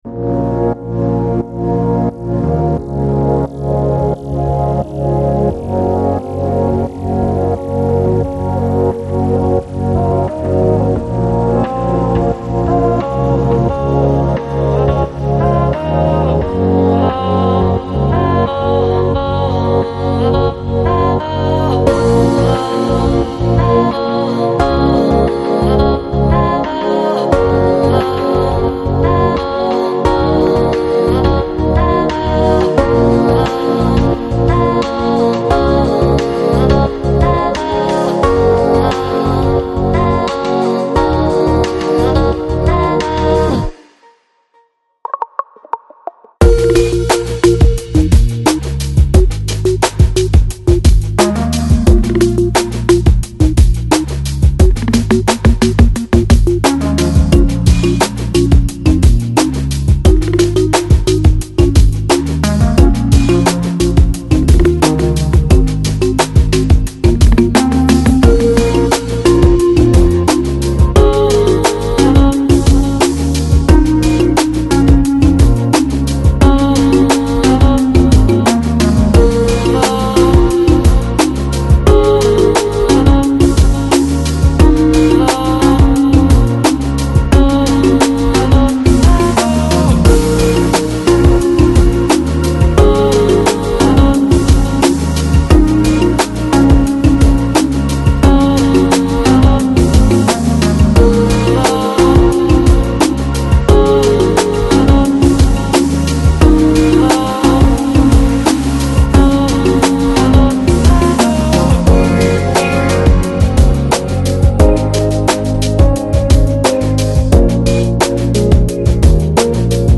Жанр: Lounge, Chill Out